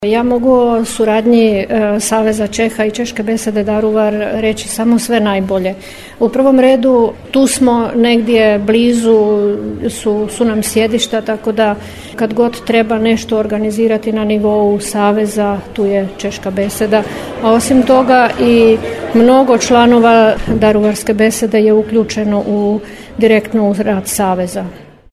Održana izborna skupština Češke besede Daruvar